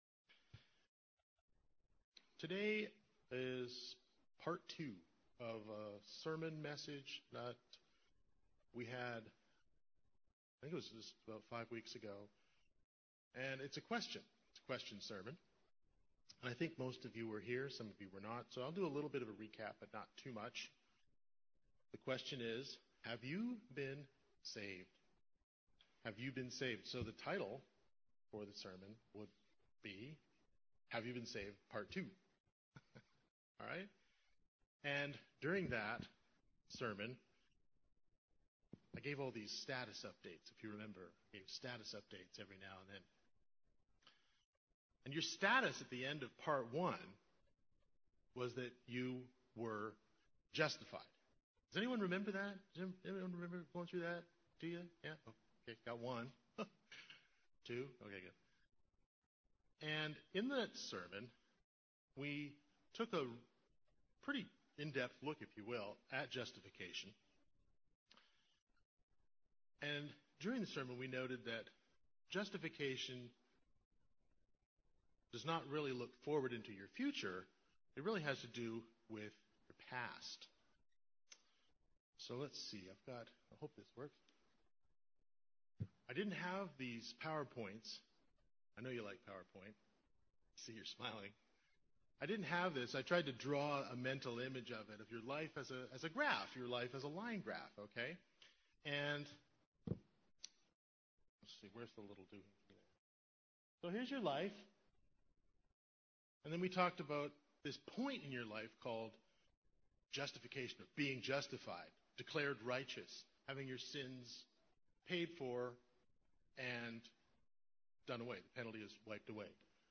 But as we noted in that last sermon justification does not look forward to your future… it has to do with your guilty past.